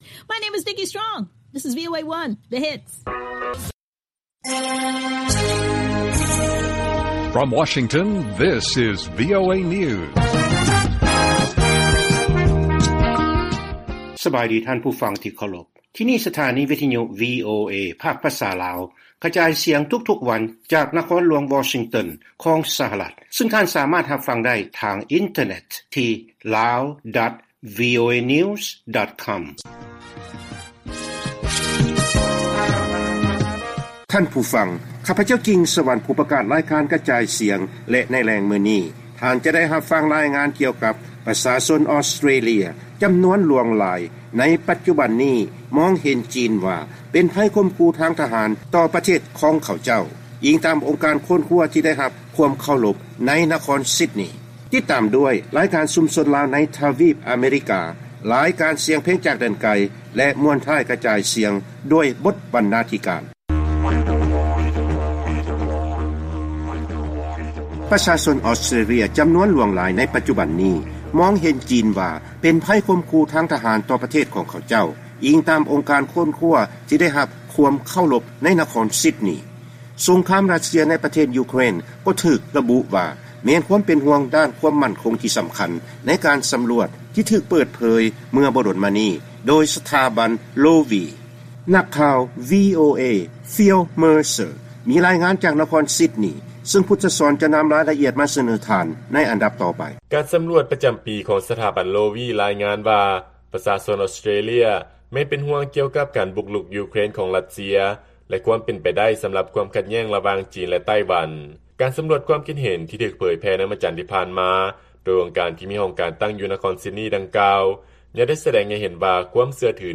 ລາຍການກະຈາຍສຽງຂອງວີໂອເອລາວ